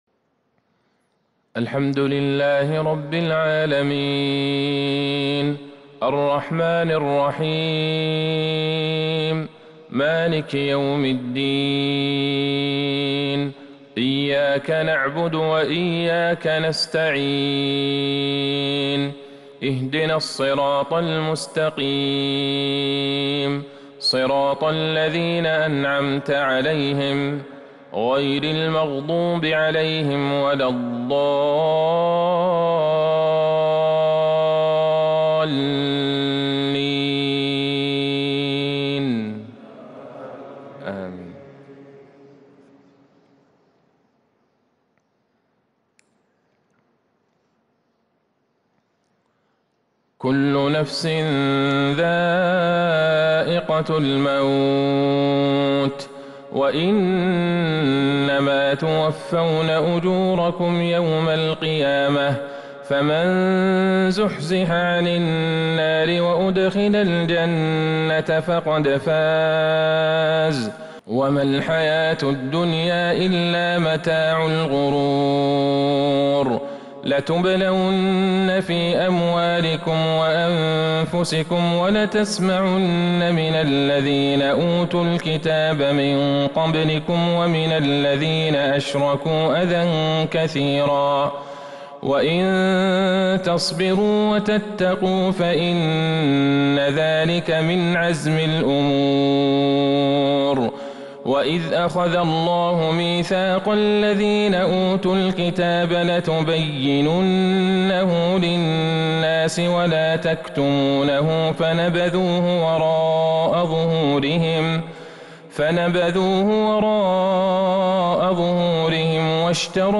فجر الخميس | ٢٦ شعبان ١٤٤٢هـ | خواتيم آل عمران | Fajr prayer from Surah Al Imran 8-4-2021 > 1442 🕌 > الفروض - تلاوات الحرمين